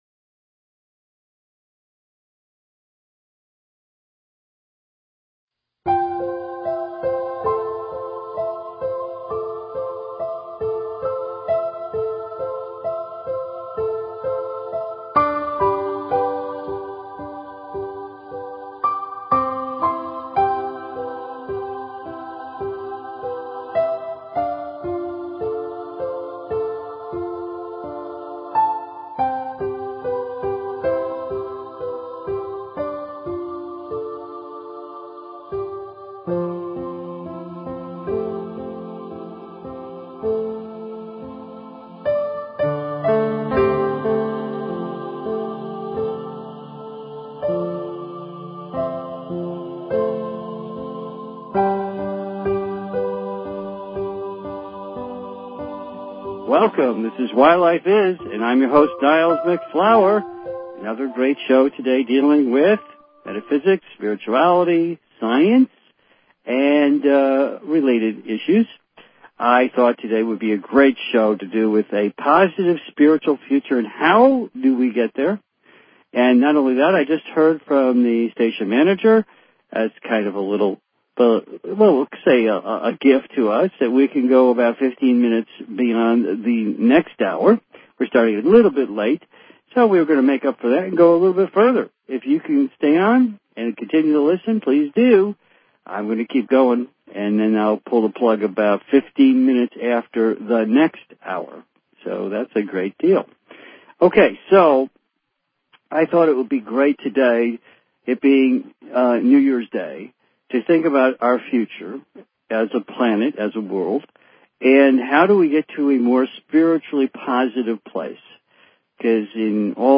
Talk Show Episode, Audio Podcast, Why_Life_Is and Courtesy of BBS Radio on , show guests , about , categorized as